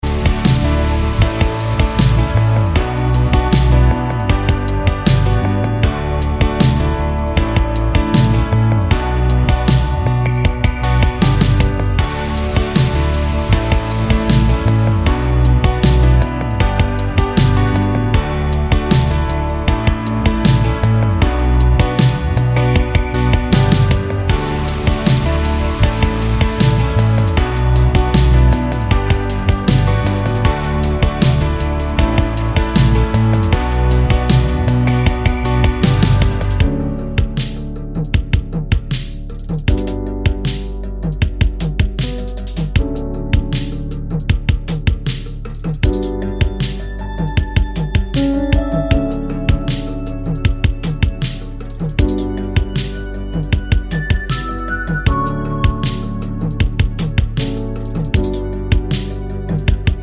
no Backing Vocals or Sample Finnish 3:45 Buy £1.50